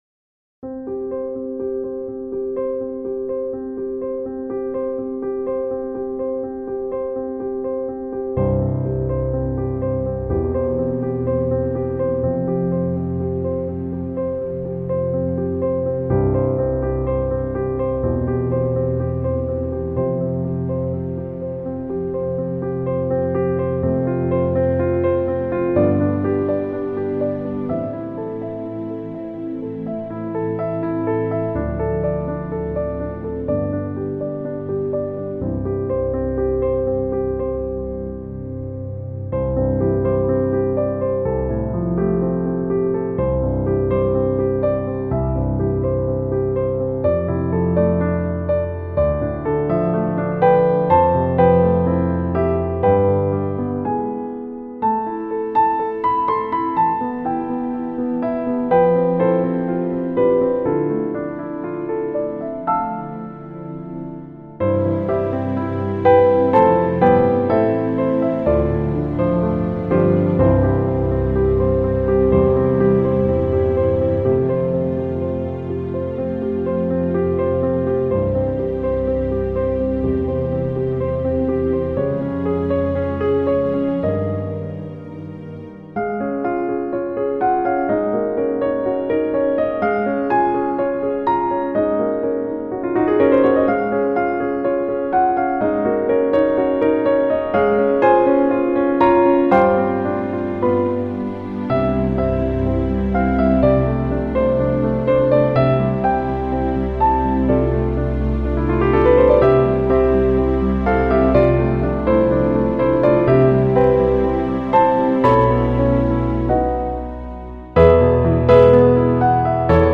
A service for 28th March 2021